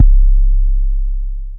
YM - Spinz 808 3.wav